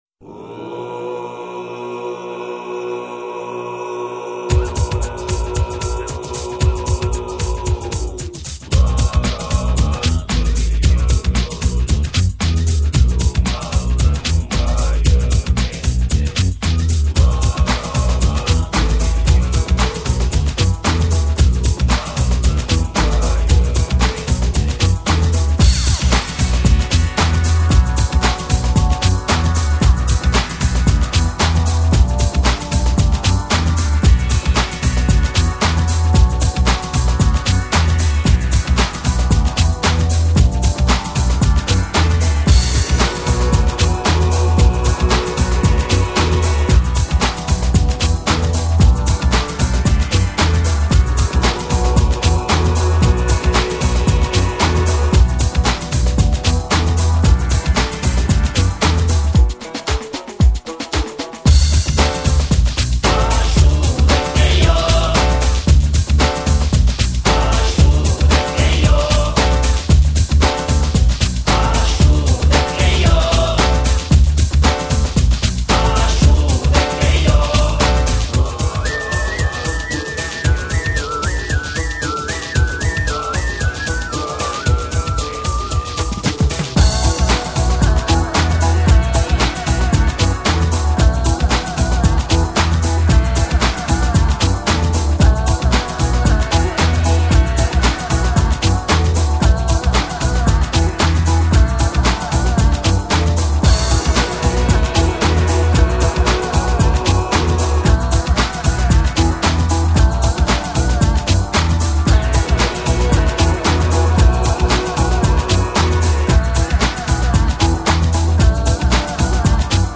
Впрочем сразу предупреждаю музыка весьма специфичная.